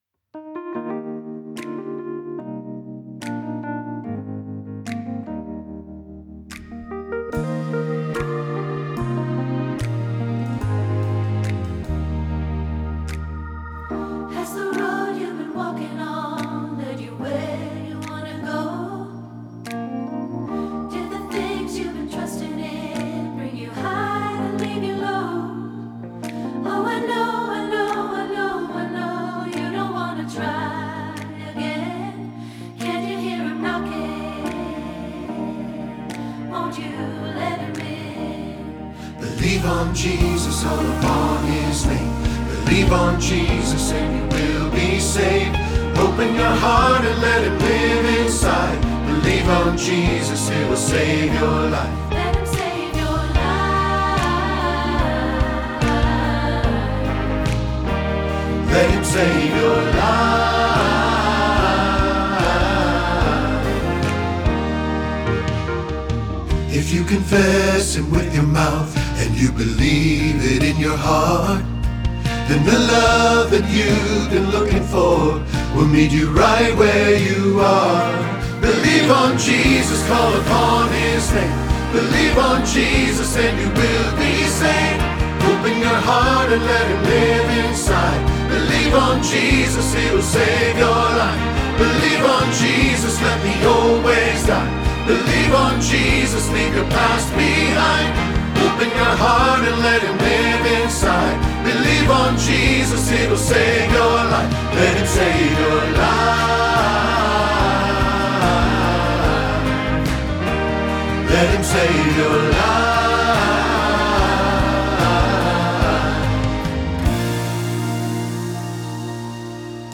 Believe on Jesus – Tenor – Hilltop Choir
Believe-on-Jesus-Tenor.mp3